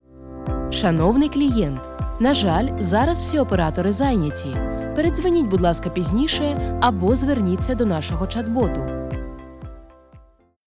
Diep, Natuurlijk, Veelzijdig
Telefonie